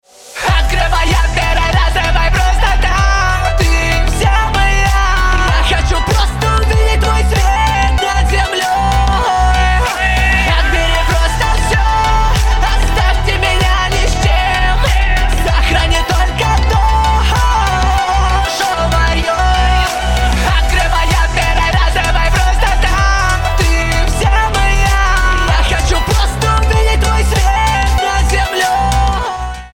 мужской голос
грустные